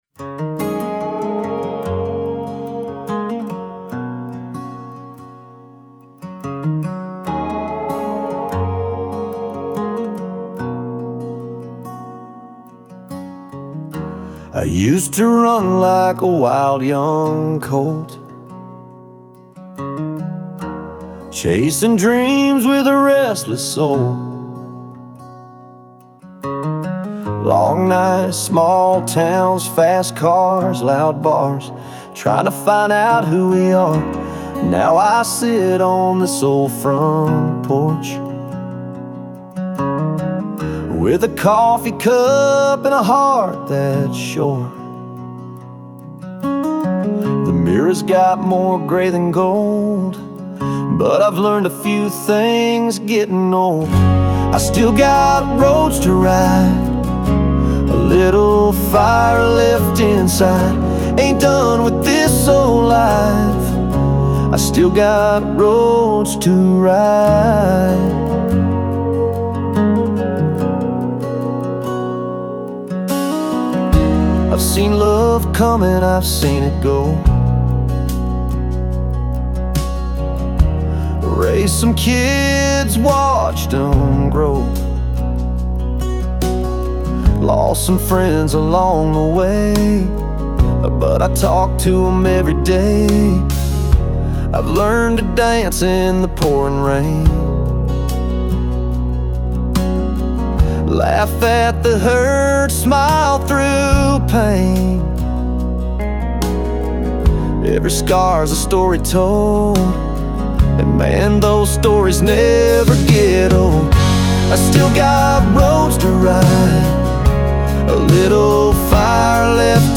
Ik gebruik een AI-tool (Suno) bij het “producen” van songs.
country muziek